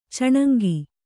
♪ caṇaŋgi